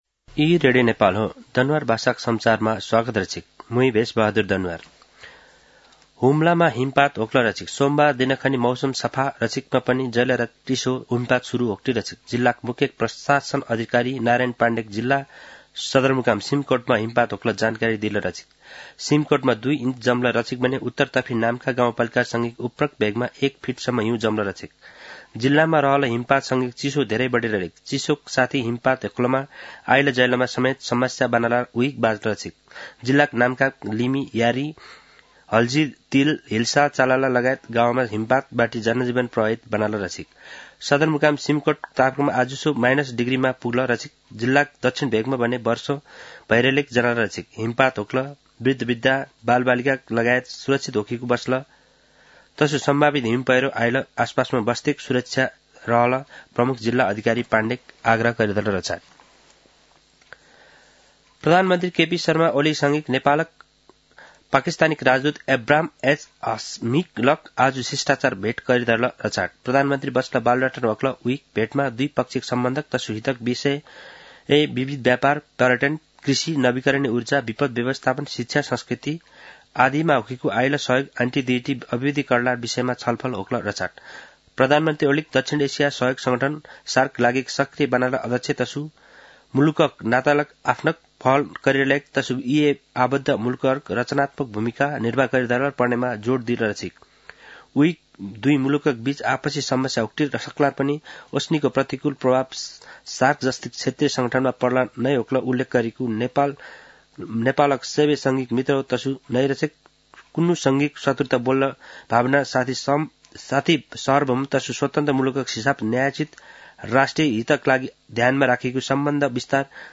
दनुवार भाषामा समाचार : १० पुष , २०८१